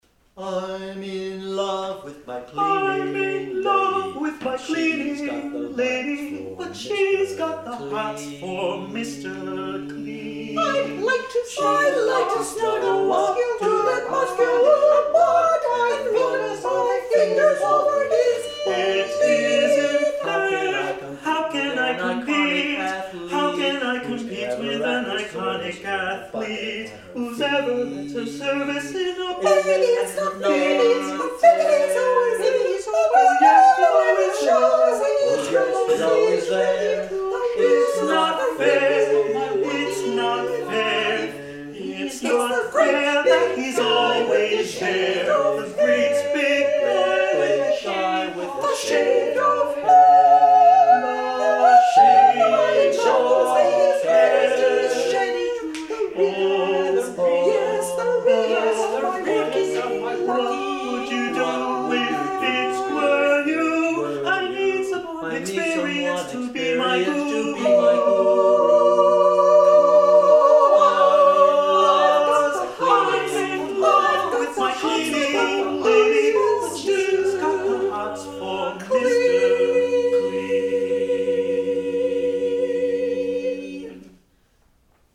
Number of voices: 4vv Voicing: SATB Genre: Secular, Art song
Language: English Instruments: A cappella